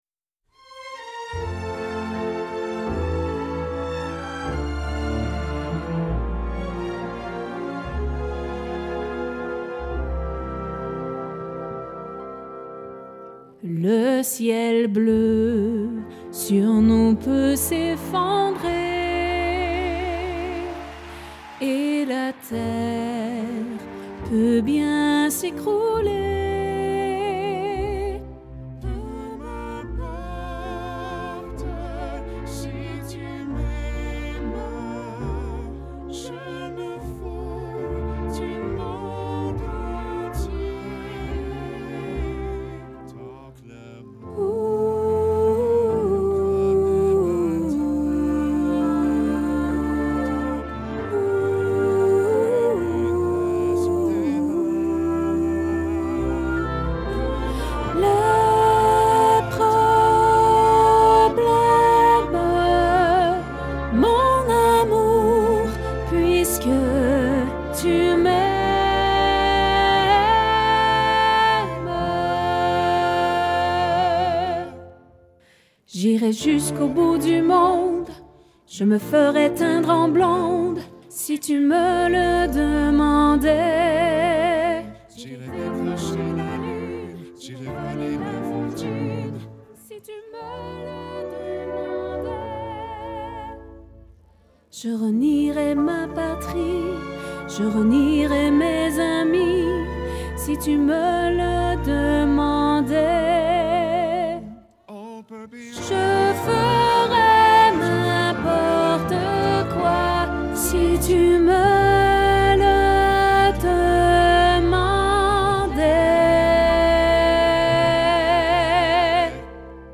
Altos
LHymne-a-lamour-Alto.mp3